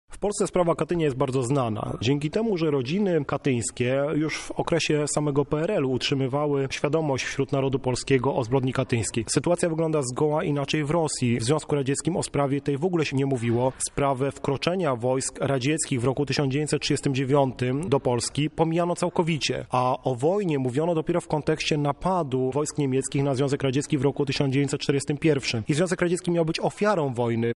O pamięci mordu w Rosji i Polsce mówi doktor